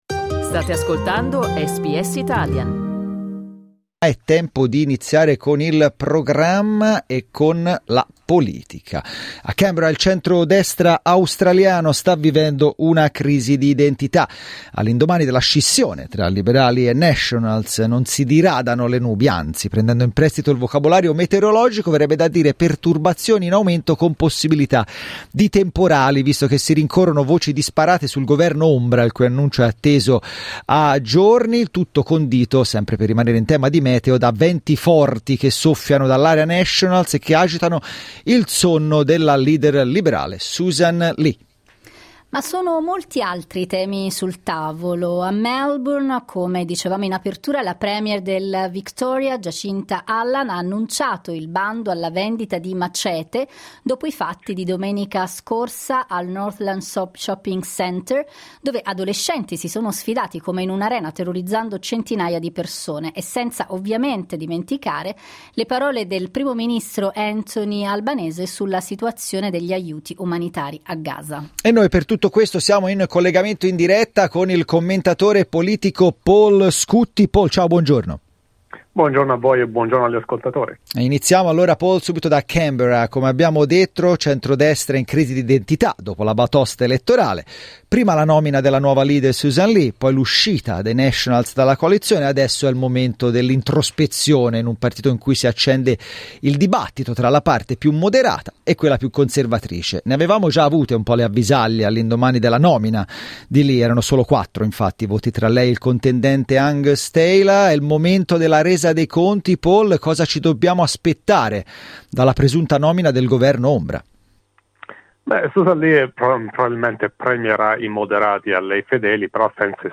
Clicca sul tasto "play" in alto per ascoltare l'intervento